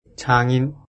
発音と読み方
장인 [チャンイン]